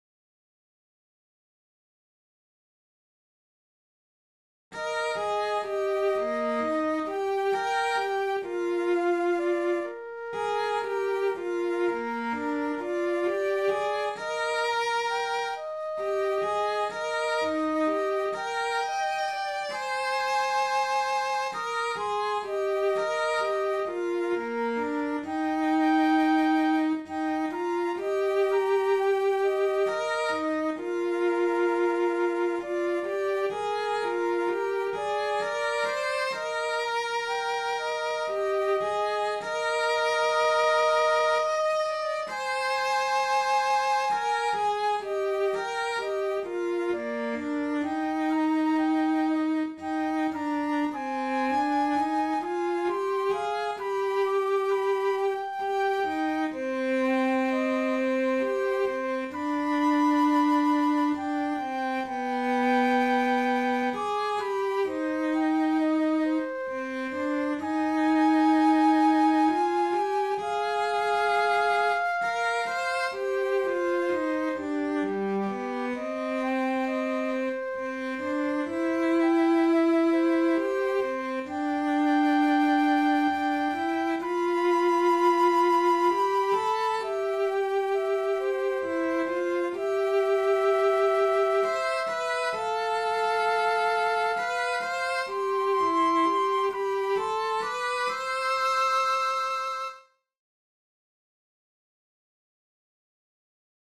Lentaa-paaskynen-ylitse-paan-sello-ja-huilu.mp3